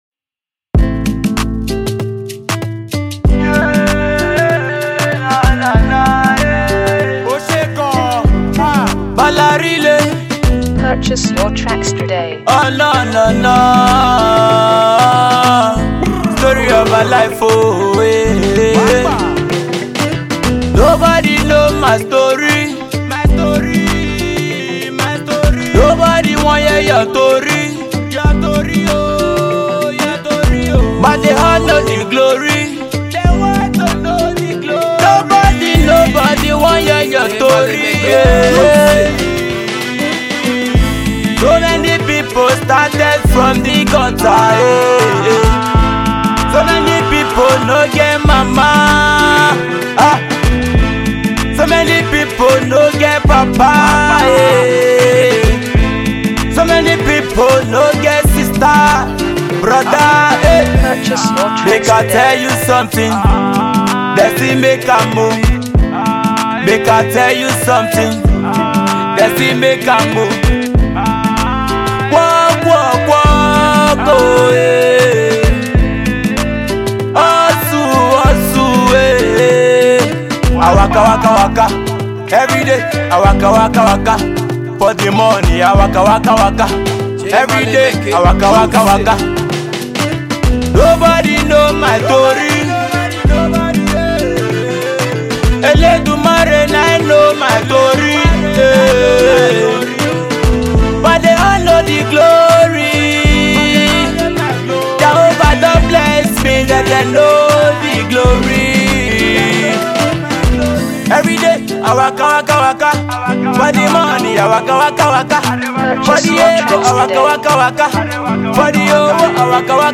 an Up and Coming Singer and Entertainer